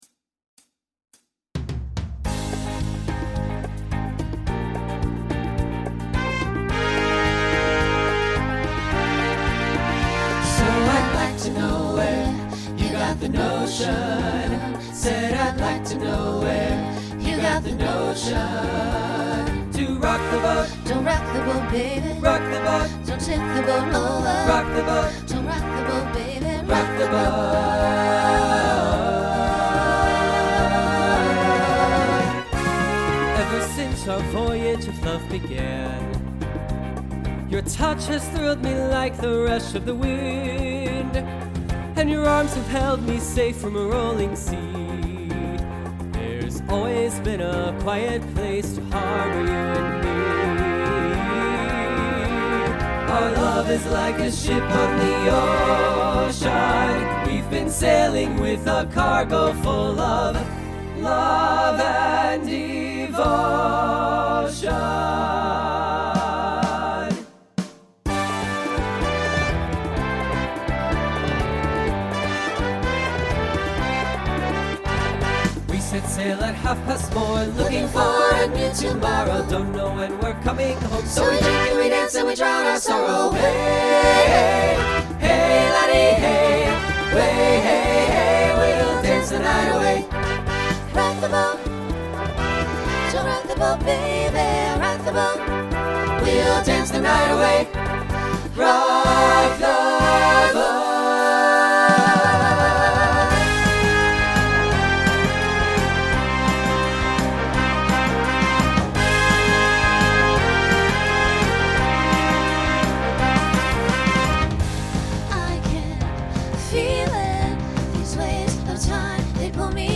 Folk , Pop/Dance , Rock
Voicing SATB